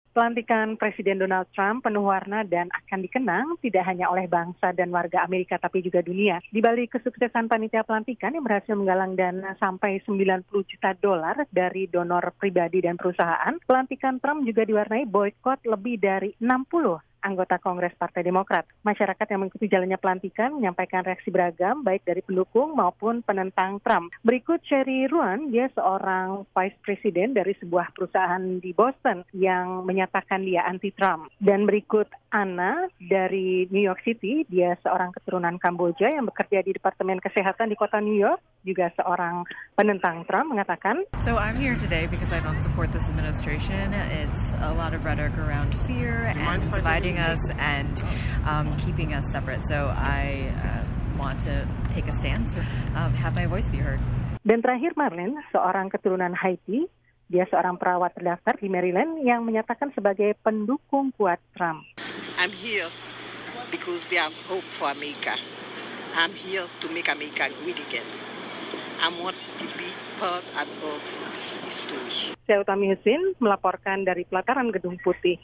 melaporkan reaksi mereka dari lokasi pelantikan di Washington DC.